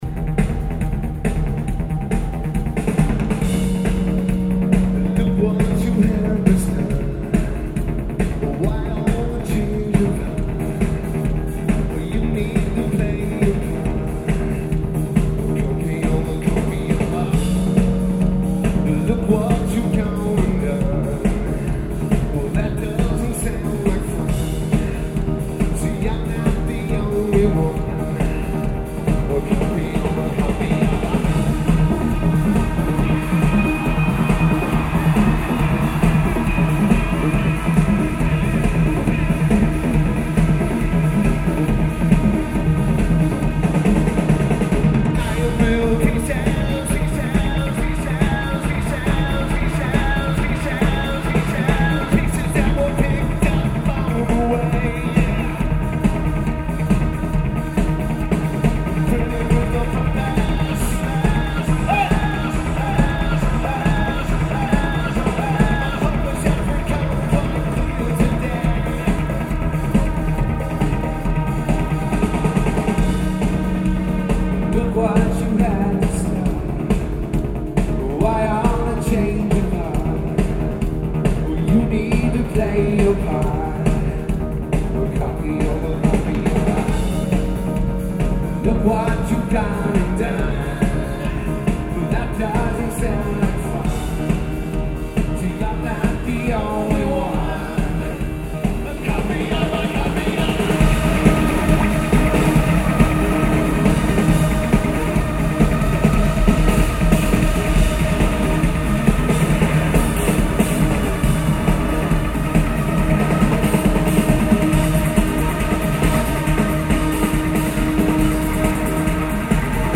Mohegan Sun Arena
Lineage: Audio - AUD (Sennheiser ME-104's + Tascam DR-07)